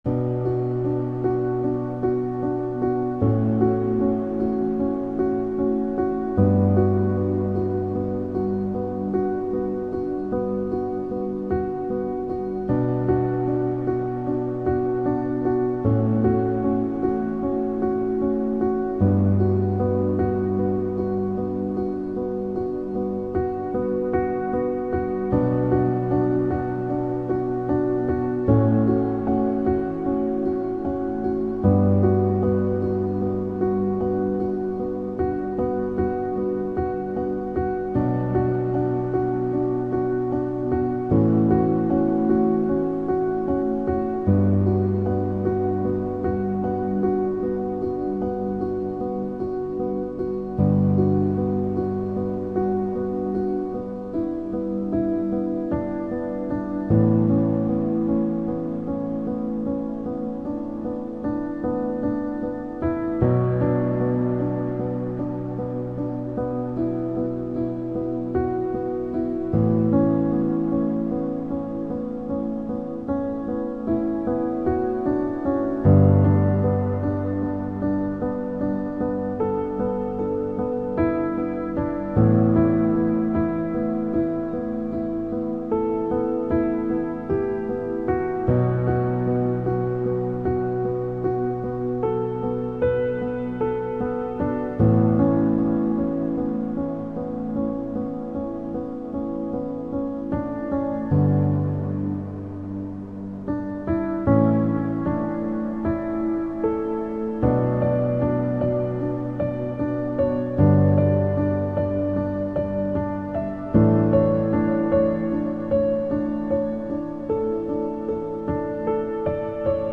آرامش بخش , پیانو , عصر جدید , غم‌انگیز , موسیقی بی کلام
پیانو آرامبخش موسیقی بی کلام غمگین